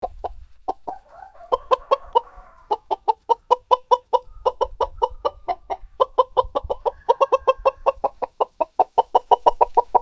Angry Chicken Cluck Fx Sound Button - Free Download & Play